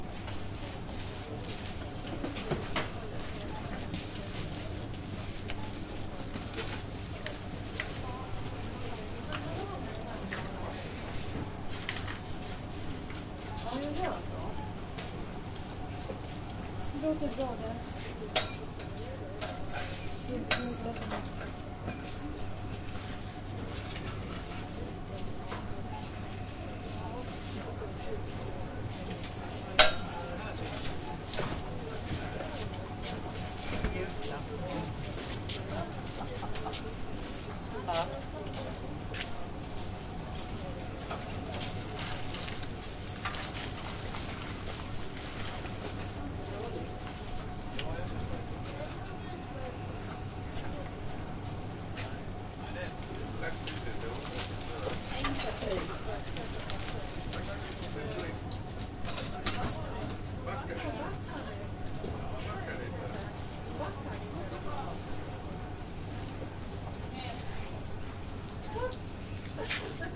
超市
描述：这是一个靠近收银台的小型英国超市。你可以听到收费的嘟嘟声和人们打包购物。
标签： 现场录音 超市 收银台 氛围 蜂鸣
声道立体声